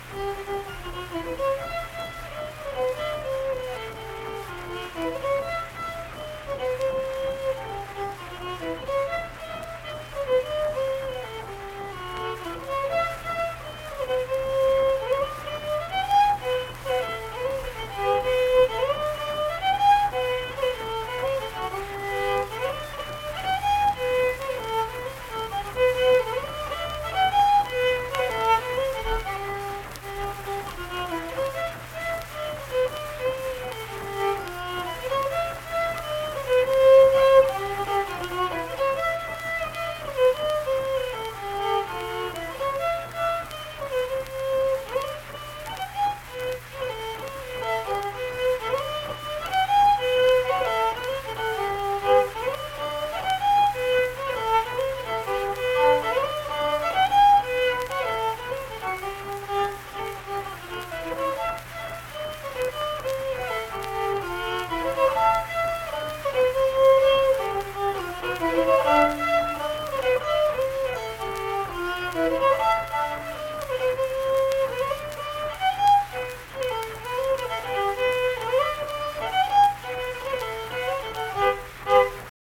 Unaccompanied fiddle music performance
Verse-refrain 3(2).
Instrumental Music
Fiddle